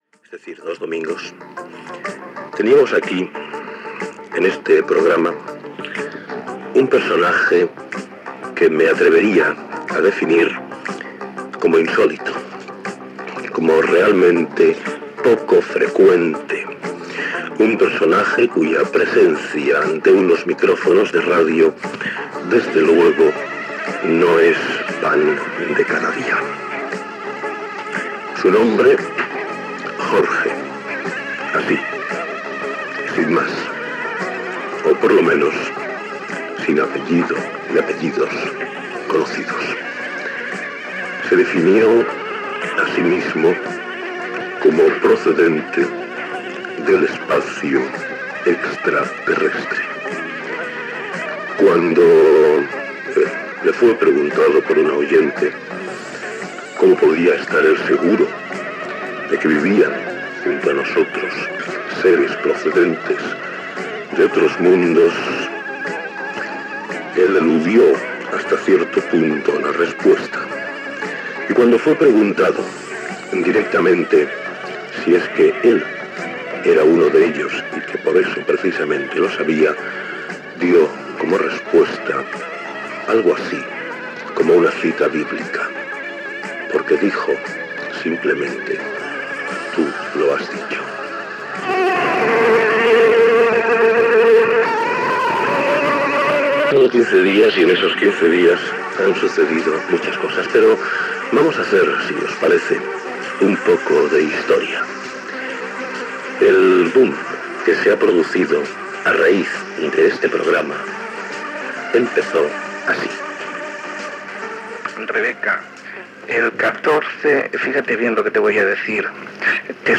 Lectura d'unes cartes rebudes posteriorment.
Comiat del programa. Tancament de l'emissió.